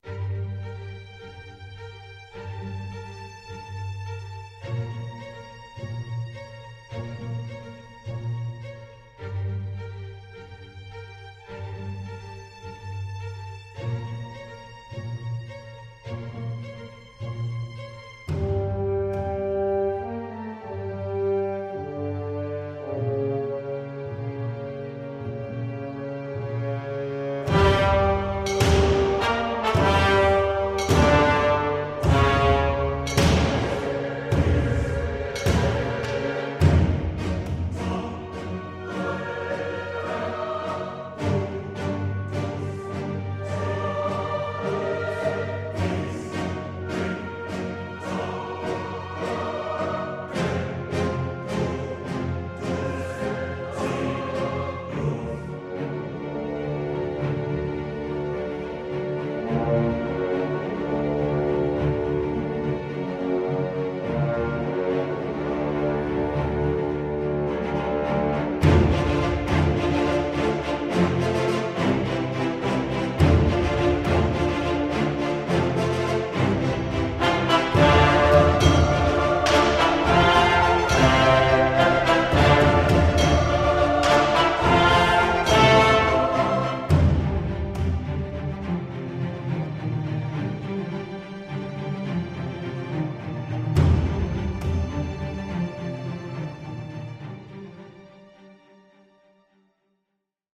alternate: more heroic